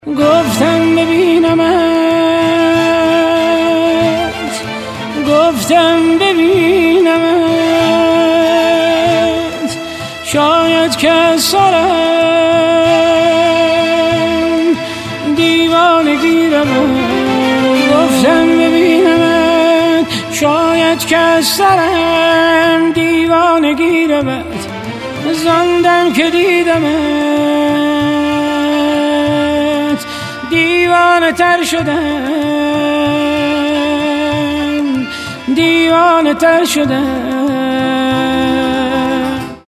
اهنگ زنگ ایرانی غمگین